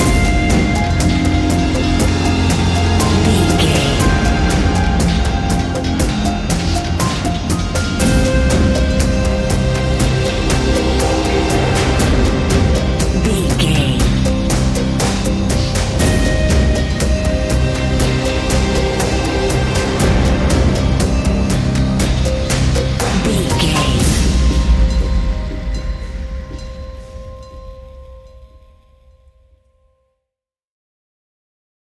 Ionian/Major
industrial
dark ambient
drone
synths